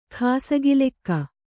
♪ khāsagi lekka